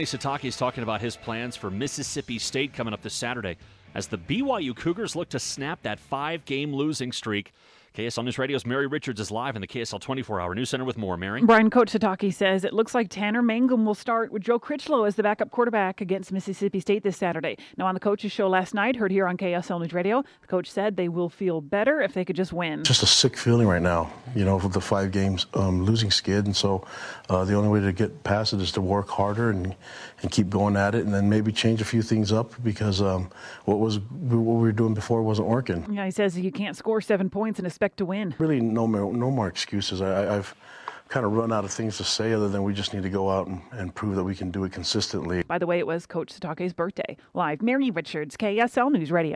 BYU head football coach talks about making changes